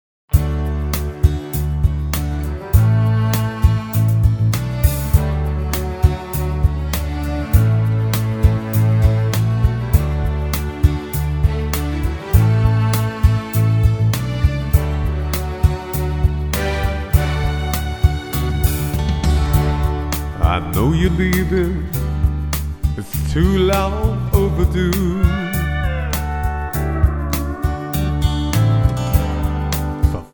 Tonart:D Multifile (kein Sofortdownload.
Die besten Playbacks Instrumentals und Karaoke Versionen .